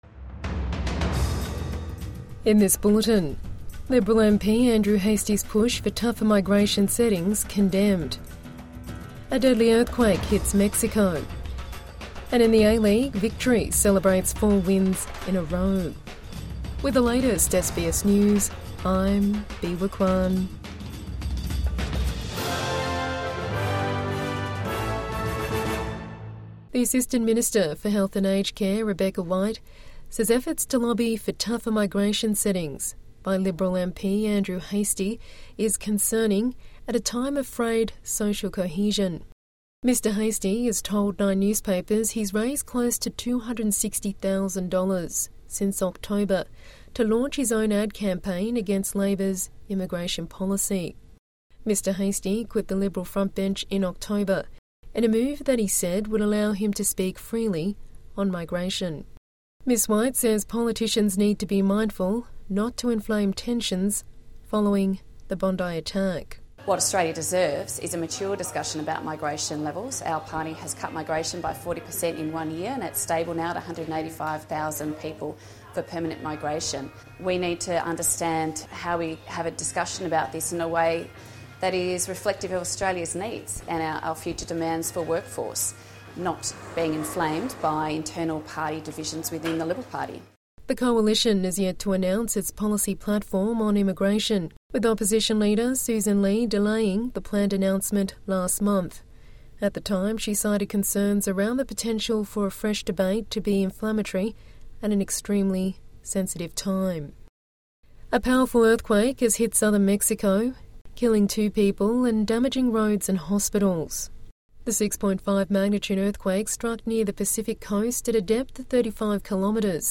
Andrew Hastie's push for tougher migration settings condemned | Evening News Bulletin 3 January 2026 | SBS News